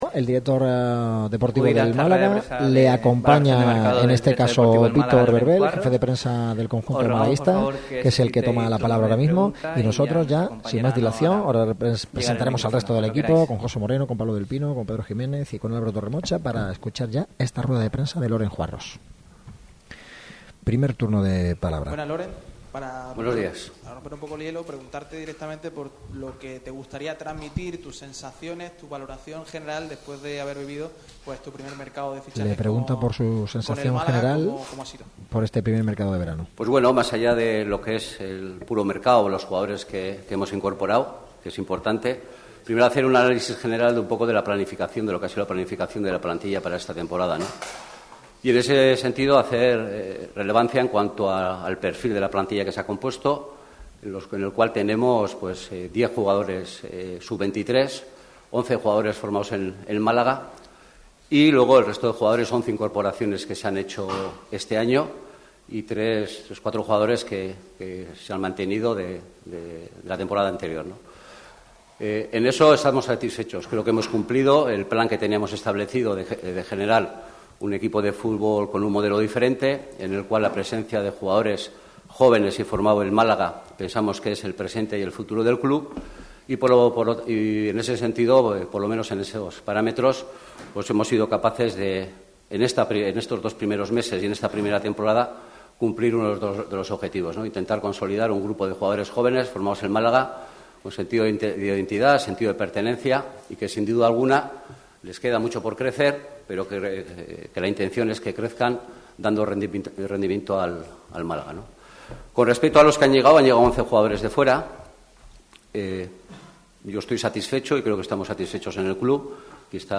Radio MARCA Málaga realizará el programa desde La Rosaleda
aquí puedes seguir en directo la rueda de prensa